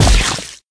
flea_egg_land_01.ogg